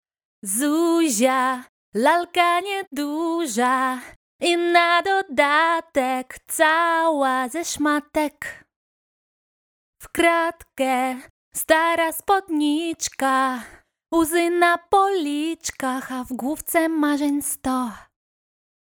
Cinsiyet: Kadın
Polish_Kid_Song_ta_.mp3